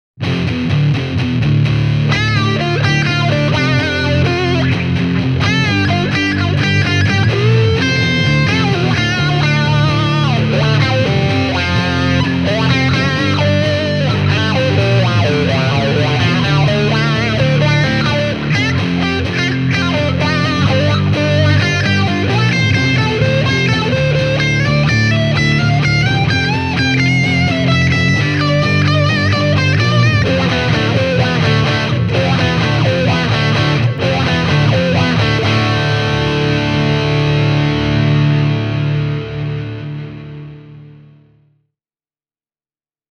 Duncanin Blackouts-humbuckerit tarjoavat runsaasti lähtötehoa ja selkeyttä, eikä ne ulise runsaalla gainella, mikä tekee niistä hyvän valinnan nyky-Metallille.